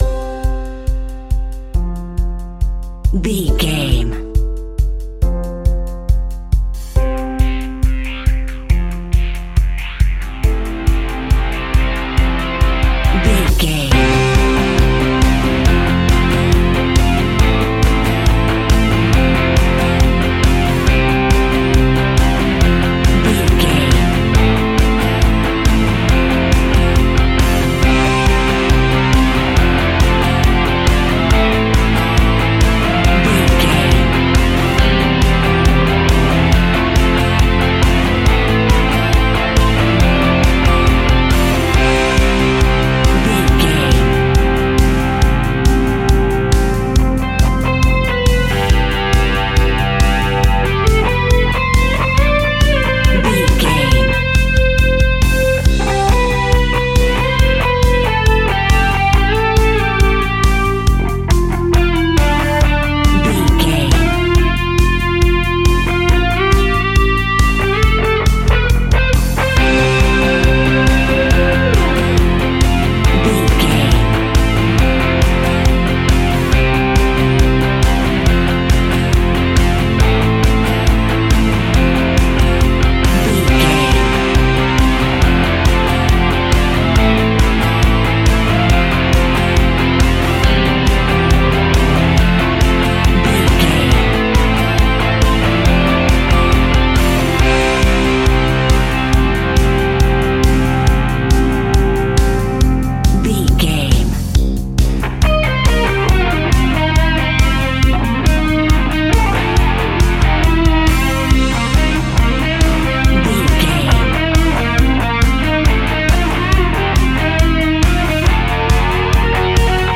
Ionian/Major
energetic
driving
heavy
aggressive
electric guitar
bass guitar
drums
indie pop
uplifting
piano
organ